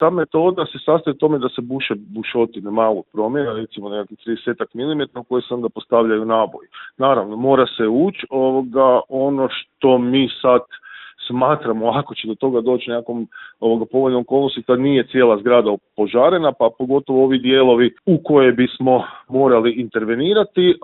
O metodama i načinu rušenja razgovarali smo u Intervjuu Media servisa